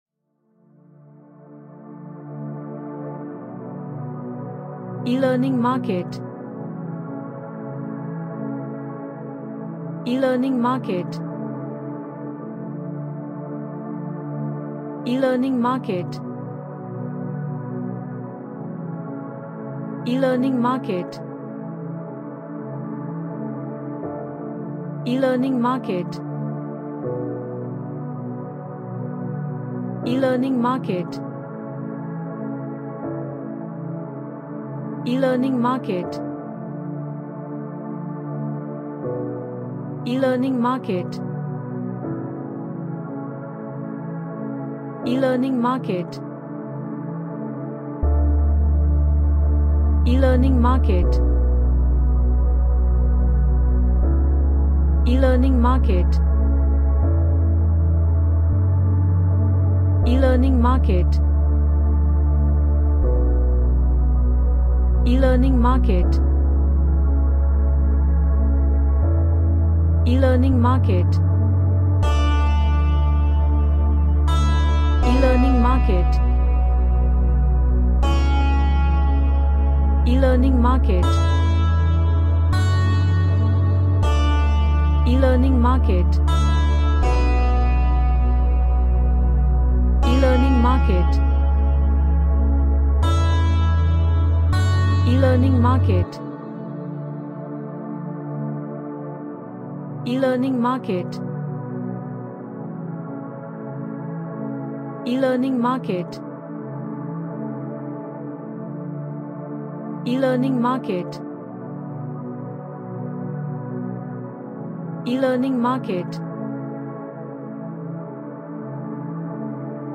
A deep meditation music
Relaxation / Meditation